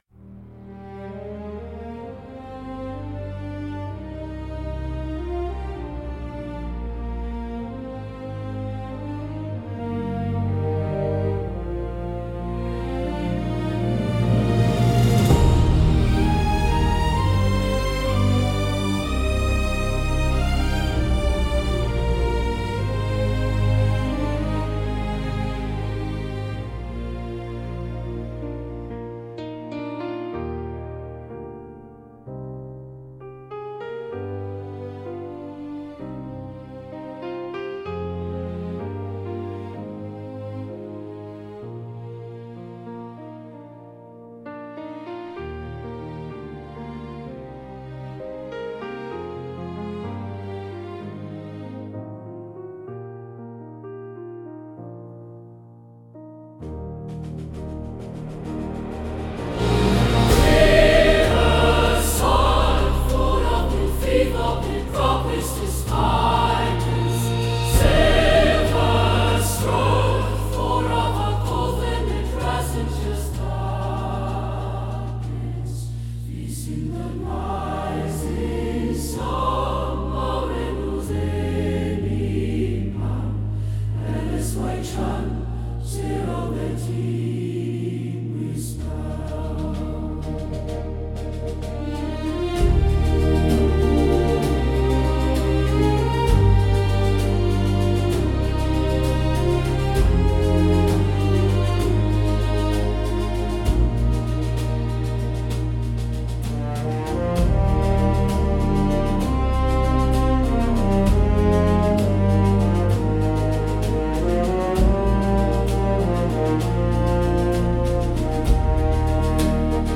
Use the Performance Track below on event day (instrumental only).
Clean Performance Instrumental